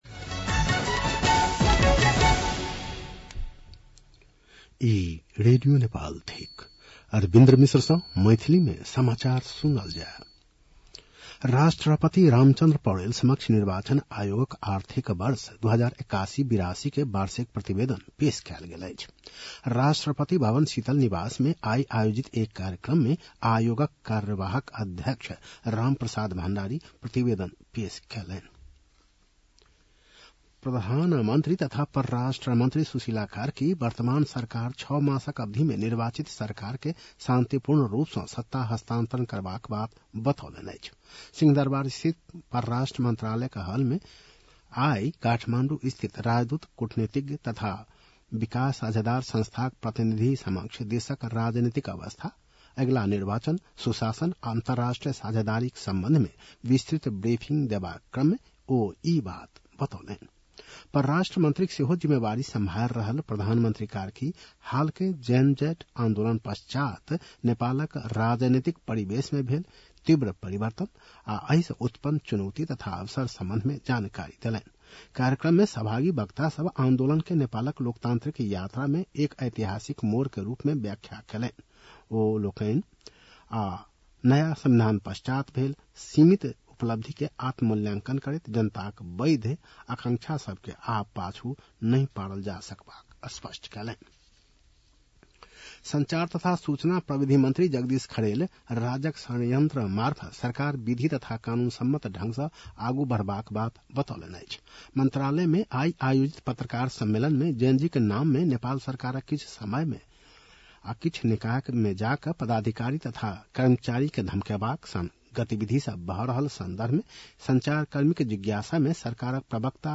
मैथिली भाषामा समाचार : ३१ असोज , २०८२
Maithali-news-6-31.mp3